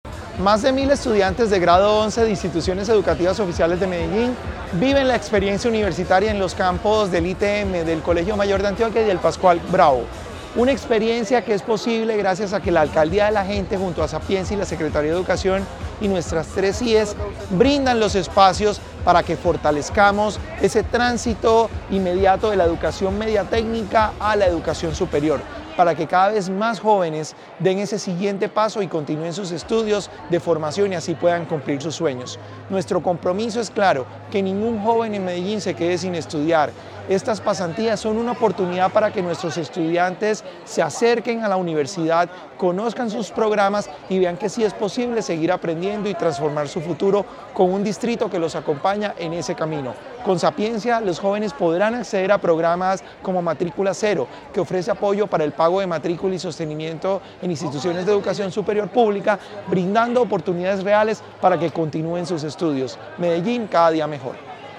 Declaraciones director de Sapiencia, Salomón Cruz Zirene
Declaraciones-director-de-Sapiencia-Salomon-Cruz-Zirene.mp3